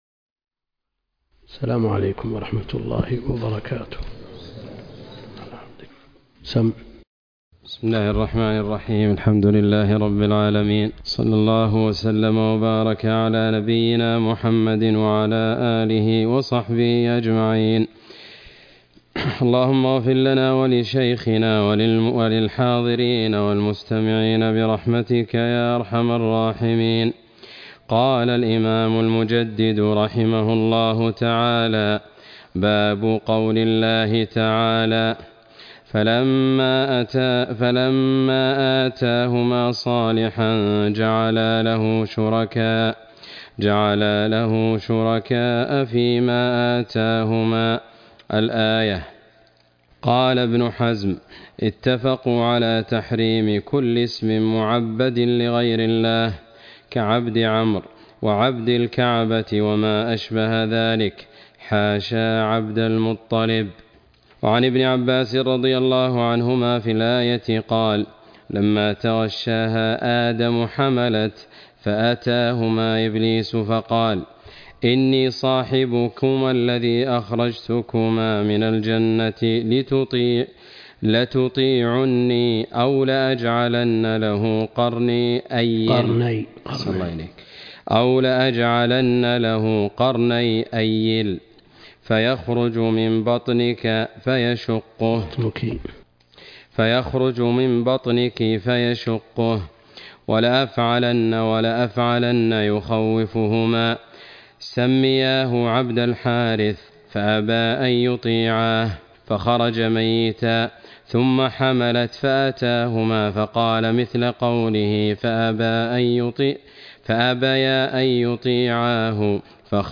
الدرس (60) شرح كتاب التوحيد - الدكتور عبد الكريم الخضير